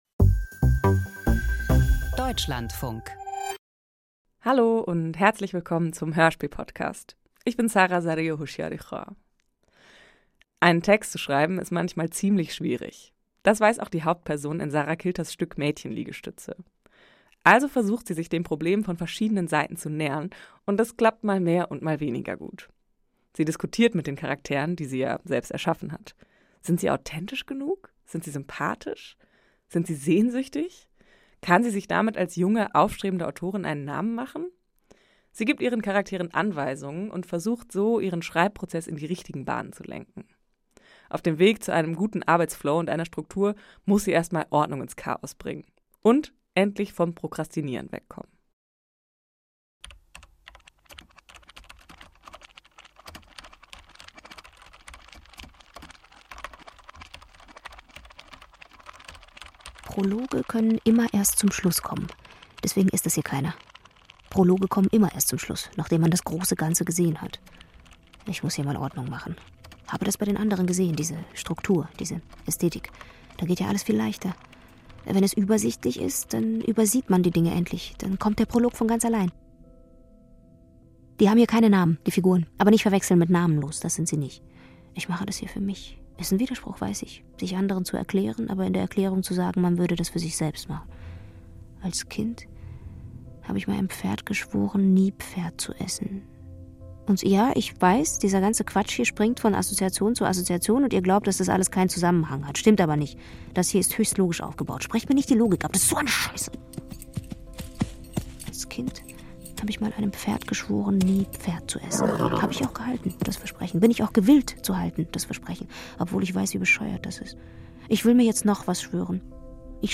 • Poesie •